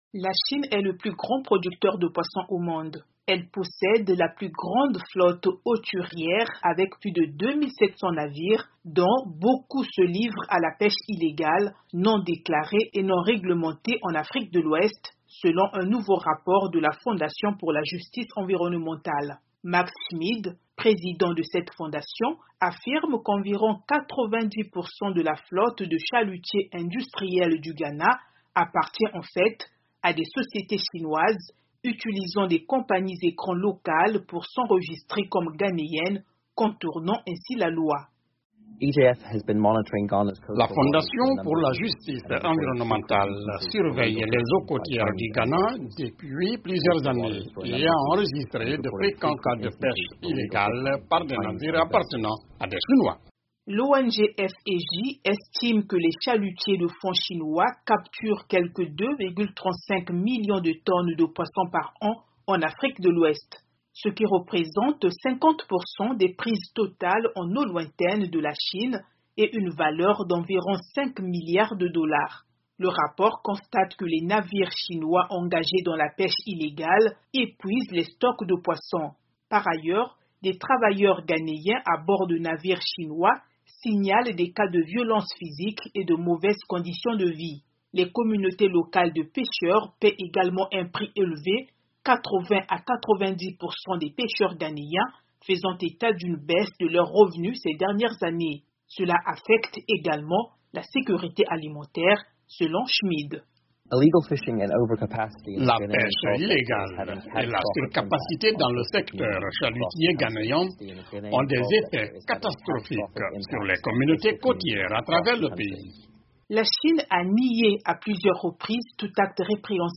Mais ce mode de vie est de plus en plus menacé, car une nouvelle enquête révèle que des navires chinois engagés dans la pêche illégale nuisent aux communautés locales et menacent le mode de vie des pêcheurs. Reportage